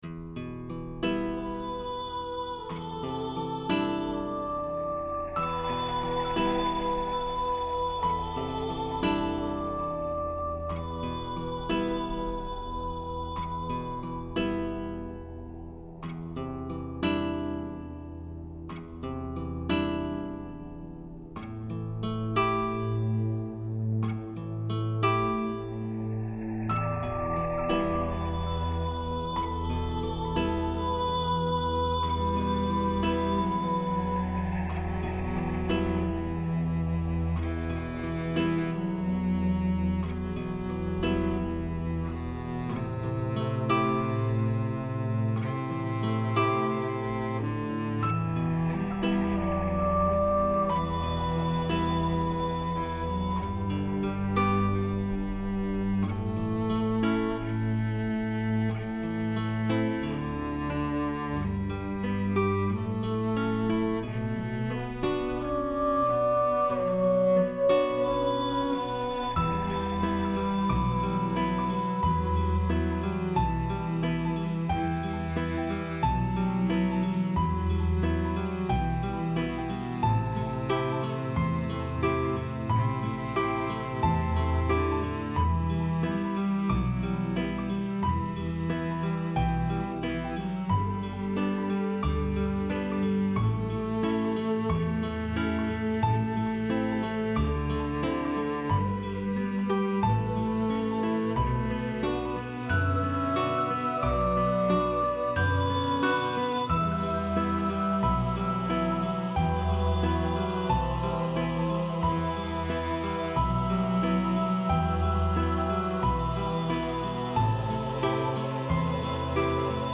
wind and deep sounds Mood: Ambient Instruments
Boy soprano Genre: World music Composer/Artist
Underscore
Reduced arrangement